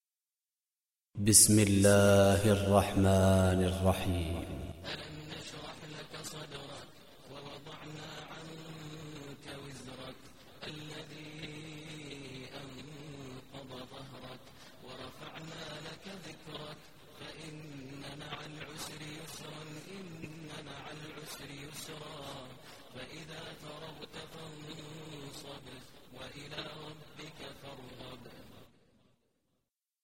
Surah Ash Sharh Recitation by Maher al Mueaqly
Surah Ash Sharh, listen online mp3 tilawat / recitation in Arabic in the voice of Imam e Kaaba Sheikh Maher al Mueaqly.